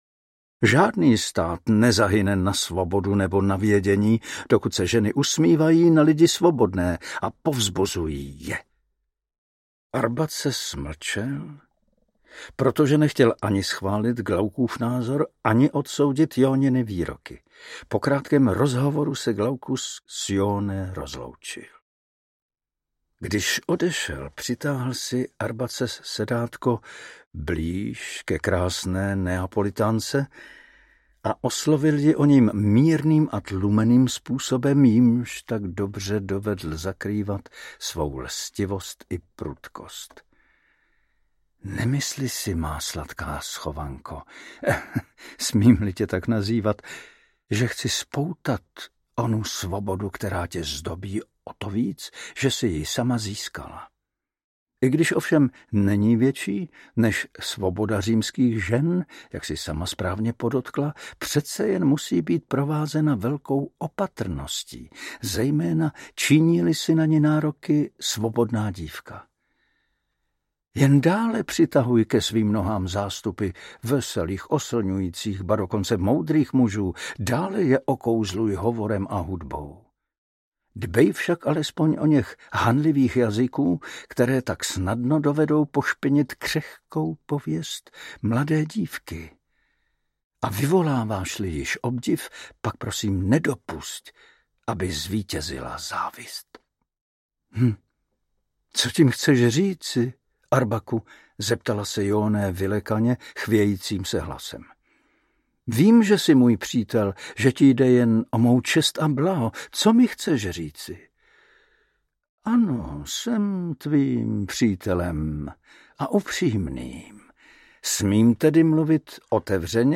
Poslední dny Pompejí audiokniha
Ukázka z knihy
Vyrobilo studio Soundguru.